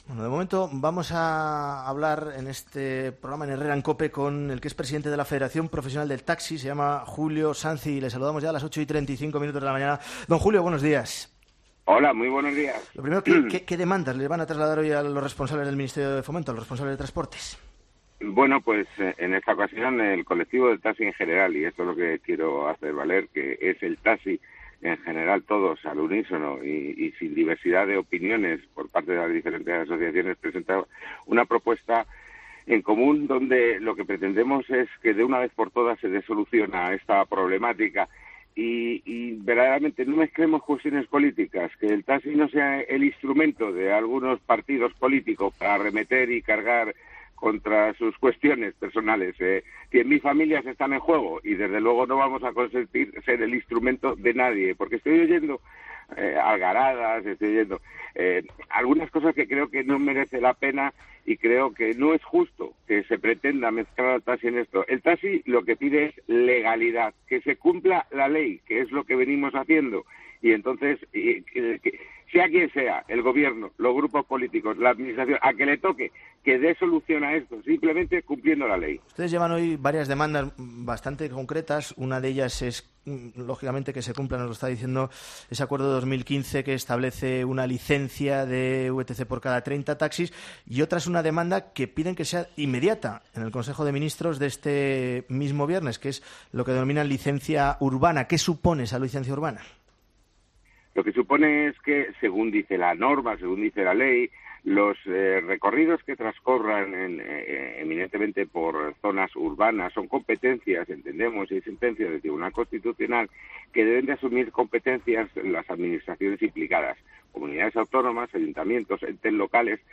Monólogo de las 8 de Herrera 'Herrera en COPE'